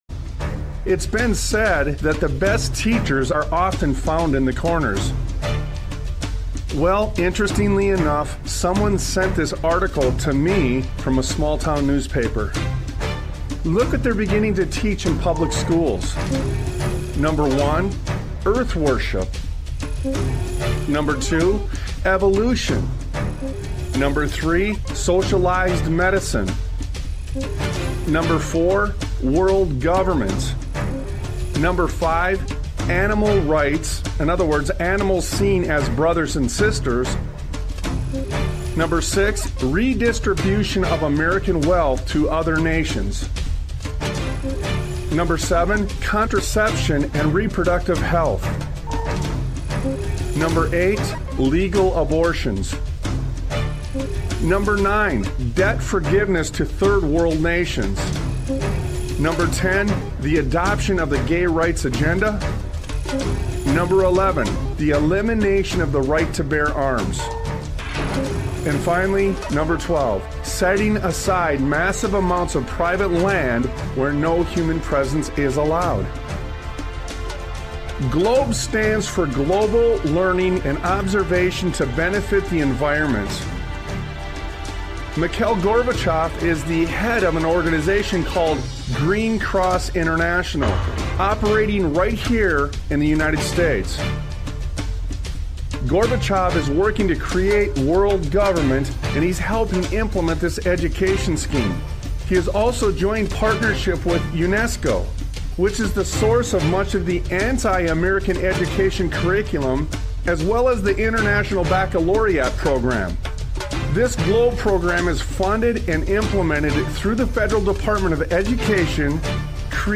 Talk Show Episode, Audio Podcast, Sons of Liberty Radio and And You Thought Our Forefathers Were Stupid... on , show guests , about And You Thought Our Forefathers Were Stupid, categorized as Education,History,Military,News,Politics & Government,Religion,Christianity,Society and Culture,Theory & Conspiracy